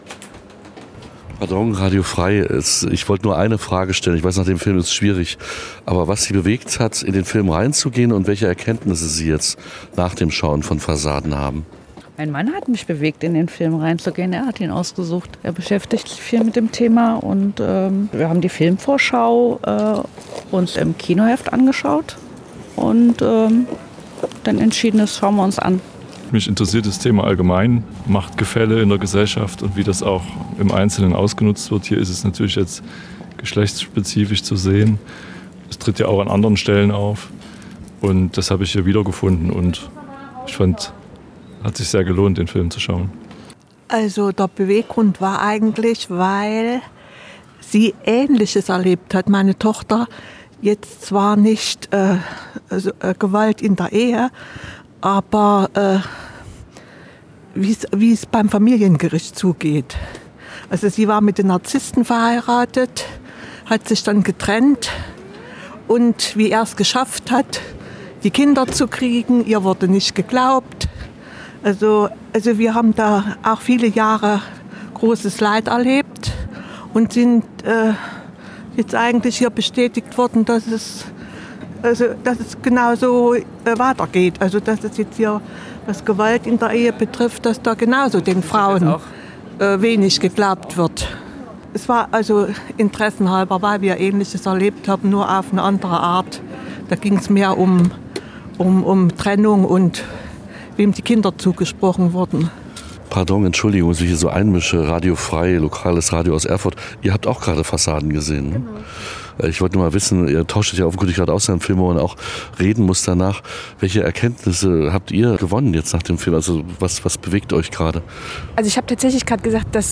UMFRAGE Fassaden.mp3